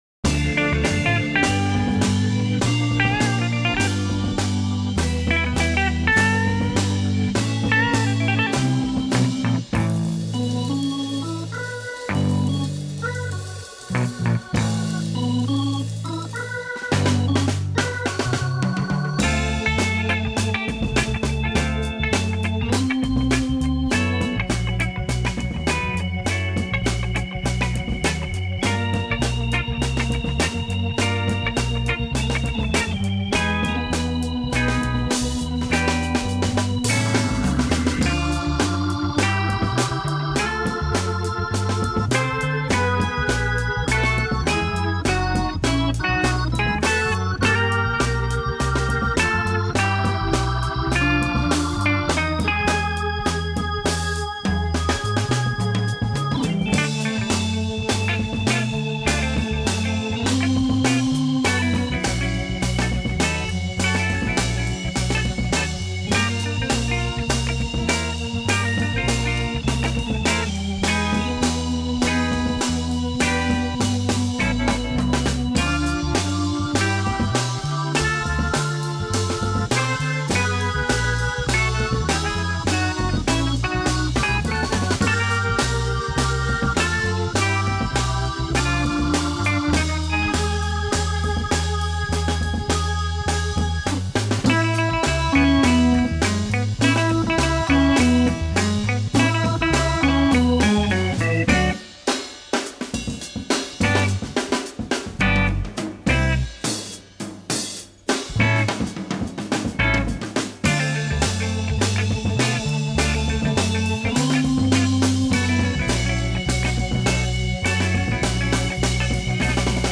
instrumental piece
keyboard
guitar stabs